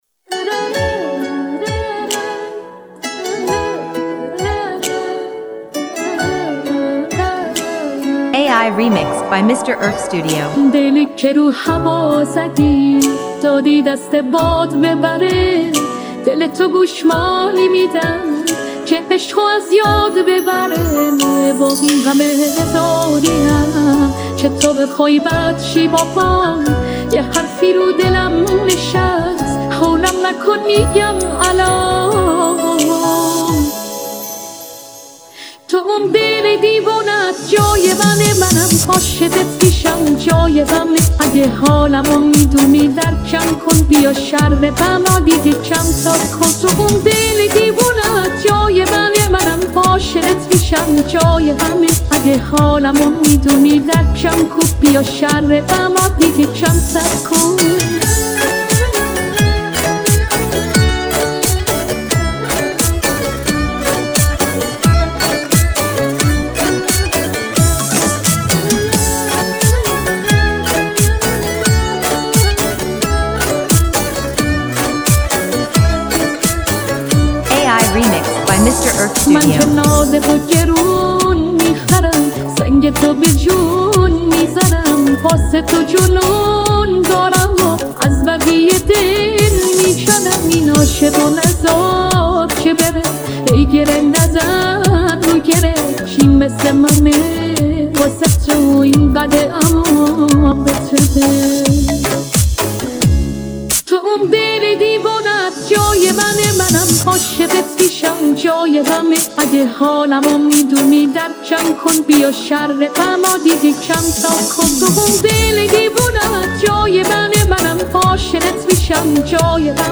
دانلود آهنگ های پاپ ایرانی پاپ هوش مصنوعی